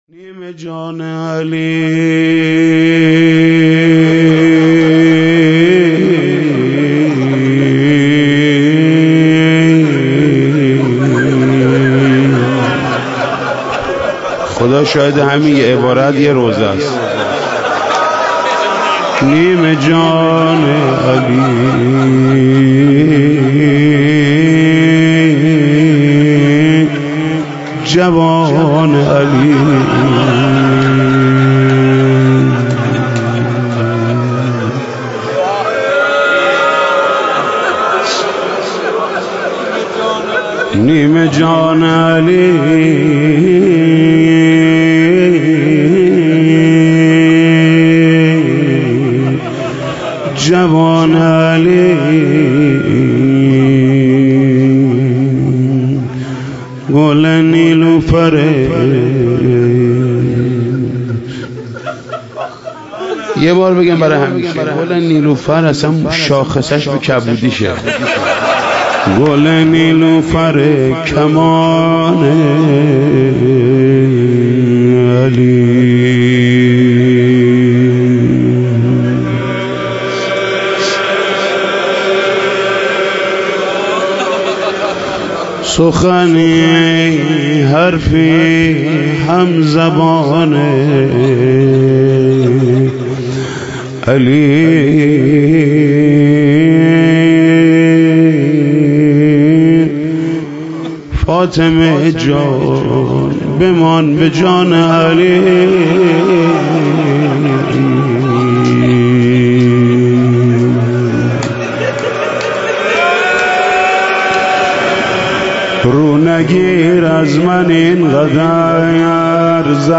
مداحی جدید حاج محمود کریمی شب شهادت حضرت فاطمه زهرا (س) ایام فاطمیه دوم هیات رایه العباس (ع) شمیرانات جمعه 19 بهمن 1397